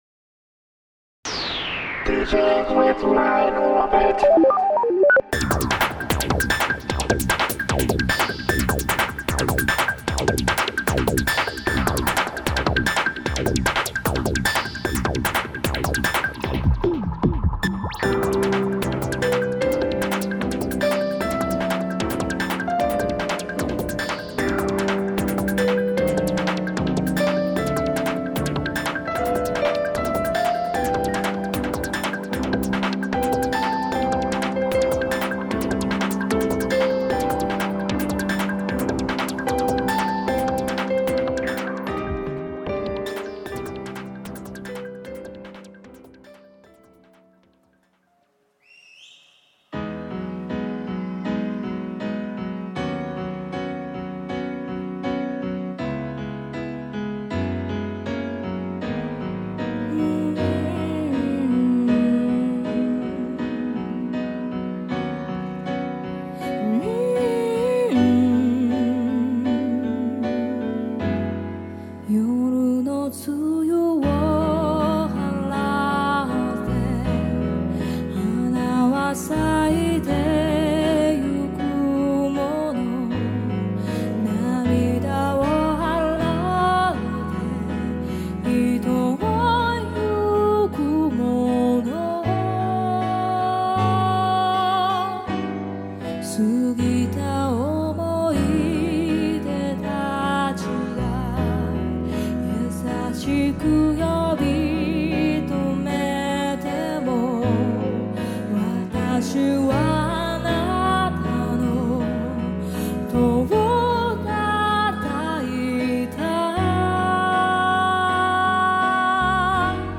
She’s a very good Singer.